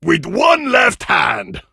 grom_kill_vo_03.ogg